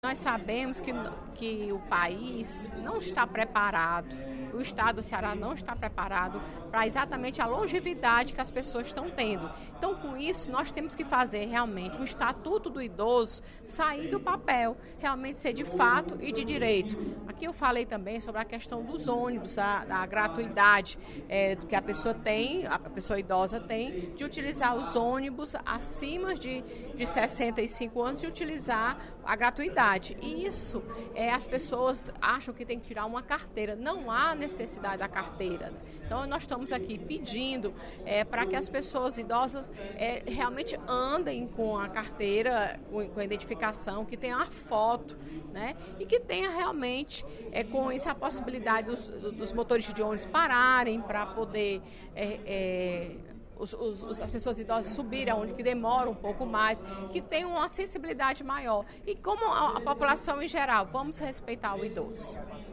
A deputada Fernanda Pessoa (PR) defendeu, nesta quinta-feira (30/06), durante o primeiro expediente, melhorias na qualidade do atendimento dos idosos e também a adequação das cidades para que os direitos dessa faixa etária sejam assegurados.